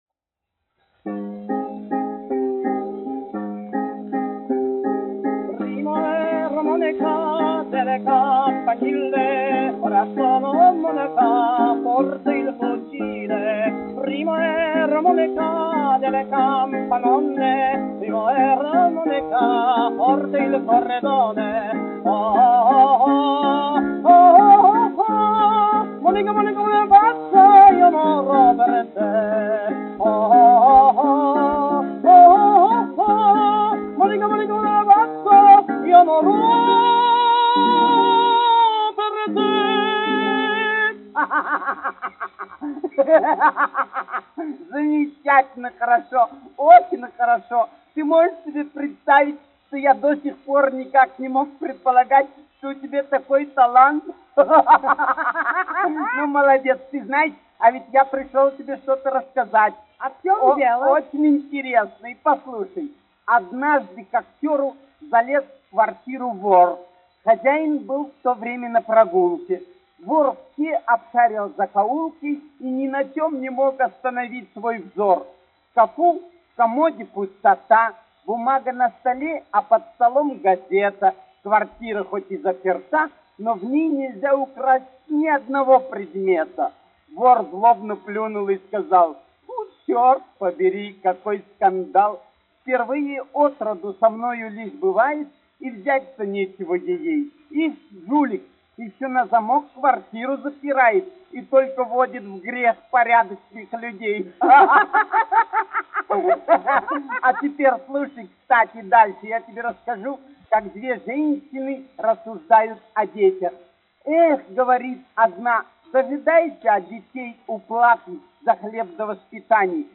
1 skpl. : analogs, 78 apgr/min, mono ; 25 cm
Humoristiskās dziesmas
Skaņuplate